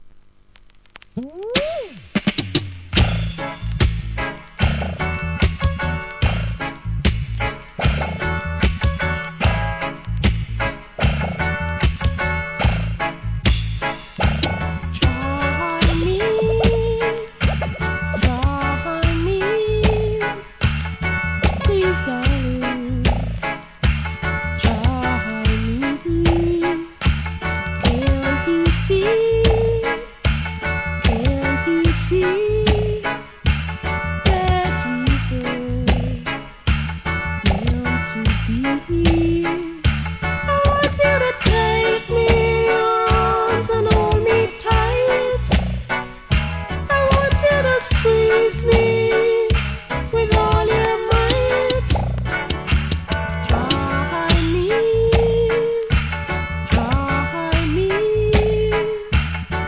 REGGAE
心地よいRHYTHMで歌い上げます♪